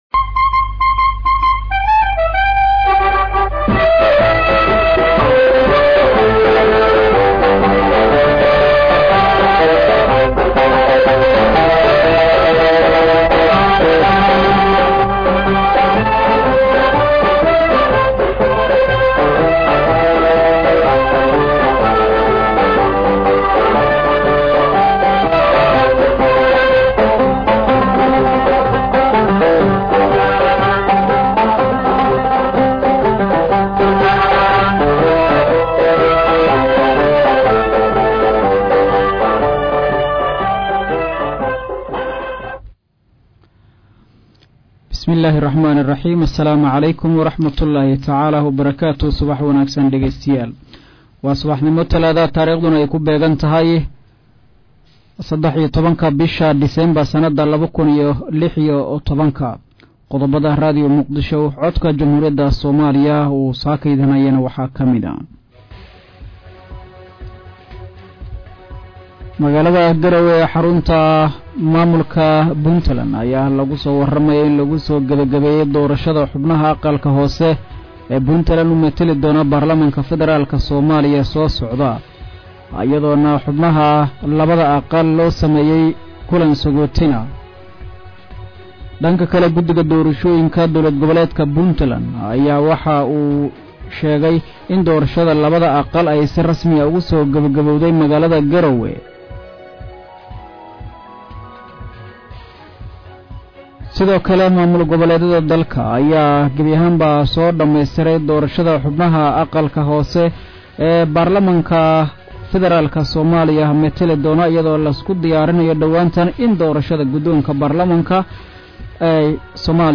Dhageyso warka subax ee Radio Muqdisho